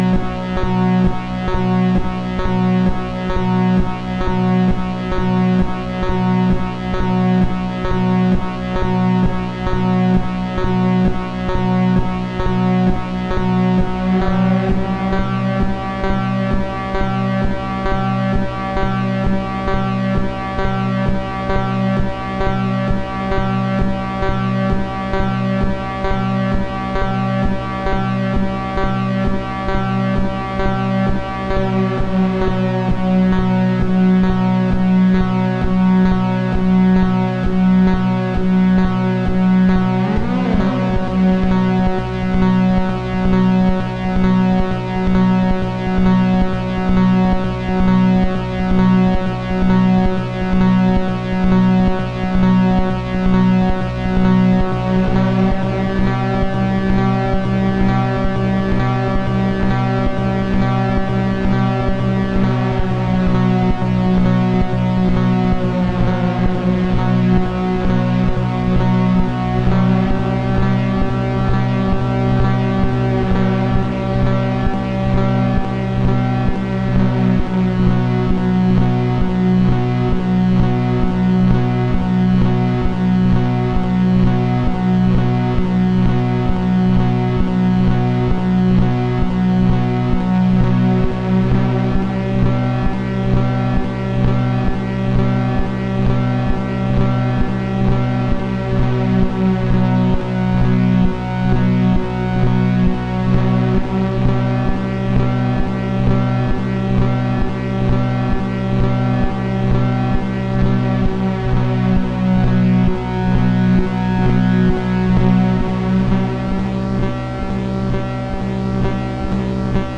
Hey, want to hear some trippy shit from my studio over the past couple months?
These are all recorded live, no editing/mixing, so they're kinda raw, but there are some beautiful instruments/sounds/processors in there that are fun to listen to.
The first one is some droney Syntrx II stuff.